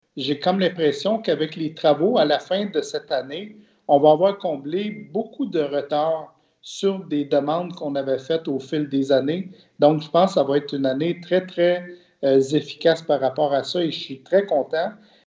Lors d’une conférence de presse virtuelle réunissant des députés de la région, le ministre responsable du Centre-du-Québec, André Lamontagne, a souligné qu’il s’agit d’une enveloppe plus importante que les 142 M$ annoncés l’an dernier.
M. Martel se réjouit par ailleurs des nombreux investissements prévus cette année dans son comté: